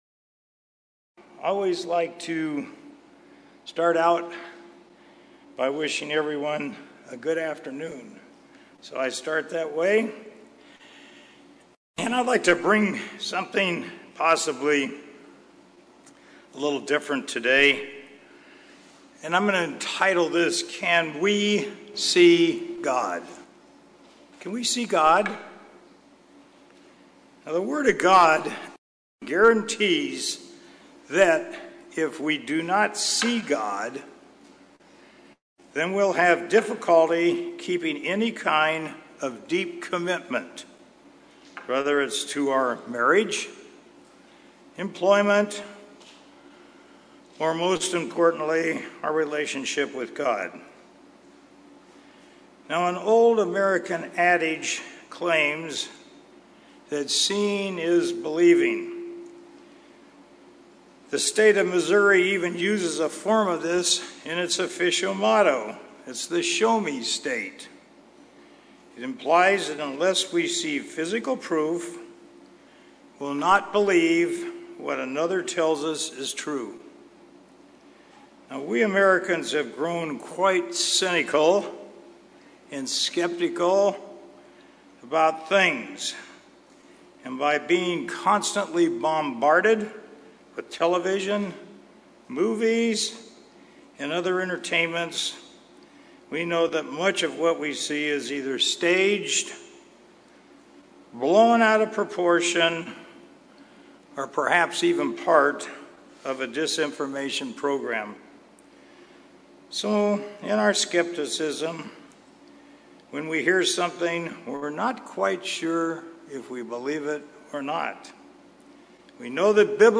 This sermon deals with the necessity and obligation of keeping God in all our thoughts.